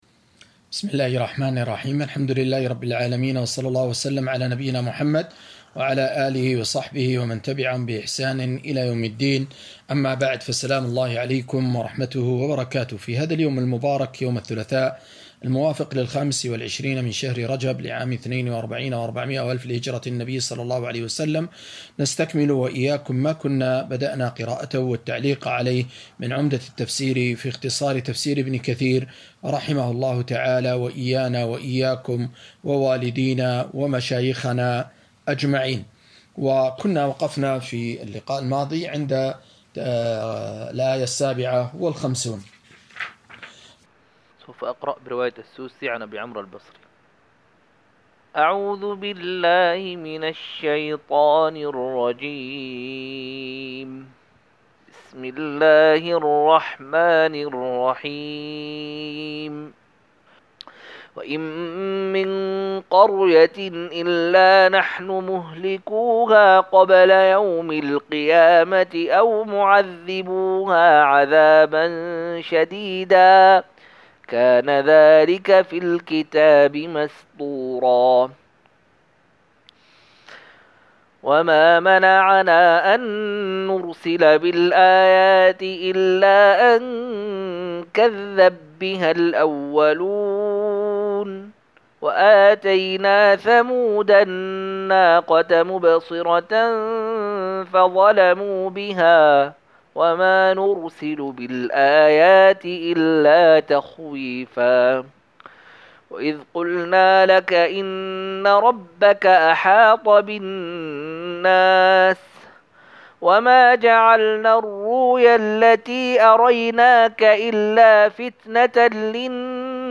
265- عمدة التفسير عن الحافظ ابن كثير رحمه الله للعلامة أحمد شاكر رحمه الله – قراءة وتعليق –